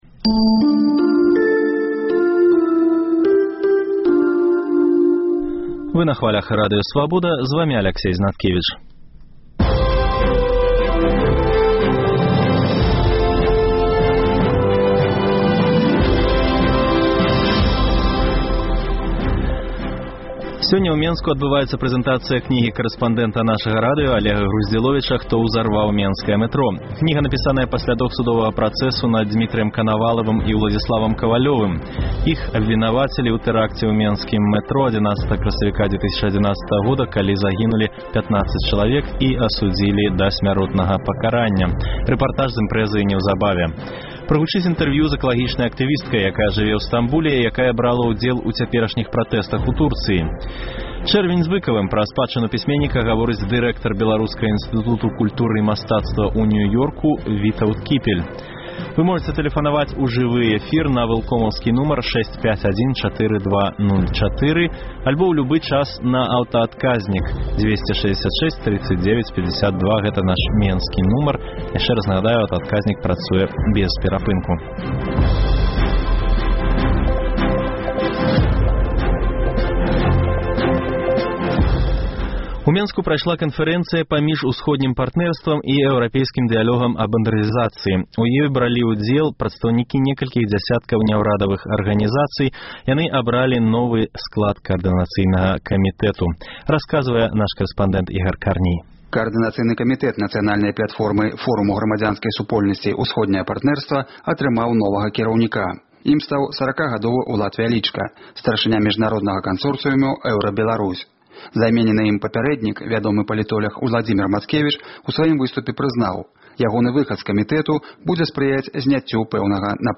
Вы таксама пачуеце інтэрвію зь беларускай, якая жыве ў Стамбуле і якая брала ўдзел у цяперашніх пратэстах у Турцыі.